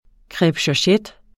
Udtale [ kʁabɕɒˈɕεd ]